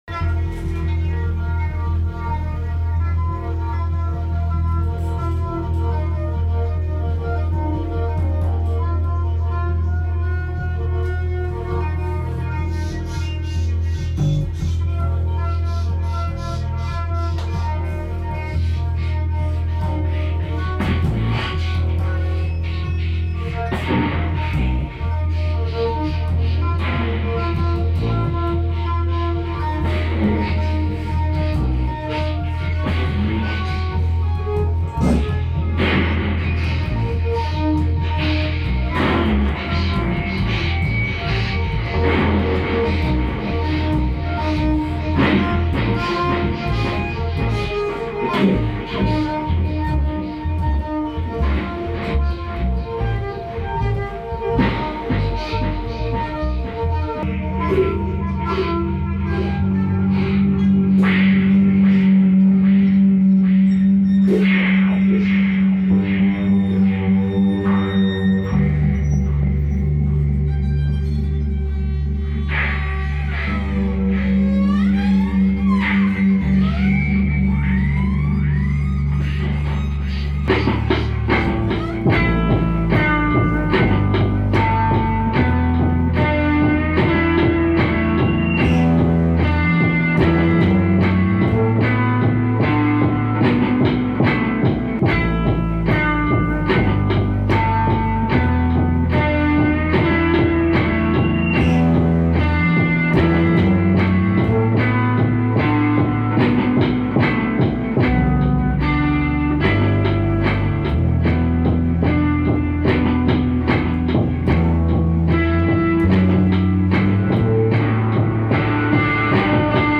a member of the experimental trio